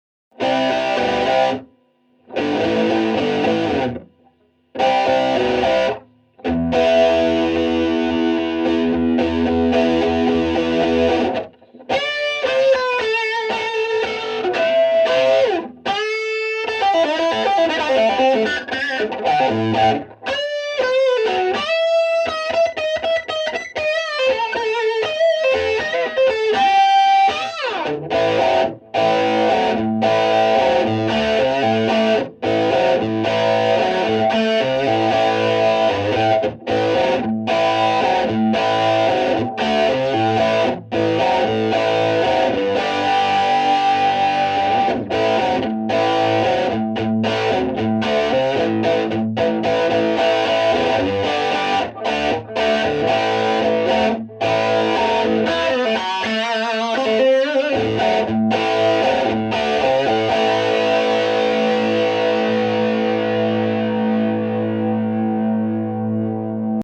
premièrement je place des mini delay (juste quelques msec) sur chacune des pistes (et valeurs différents !)
>gratte-stereo.... ehh oui on a crée un son stéréophonique à partir d'une source mono !! c'est merveilleux ! expérimentez largement avec les différentes valeurs de delay et de pitch avant d'aller plus loin (dans mon exemple l'effet chorus du pitch commence déjà à se faire entendre... des réglages plus doux et plus discret sont évidemment possible !!); vous verrez que les possibilités semblent être inépuisables et toutes les nuances entre un effet à peine perceptible et des effets de Chorus et/ou de Slap franchement audible et plus ou moins prononcés seront dispo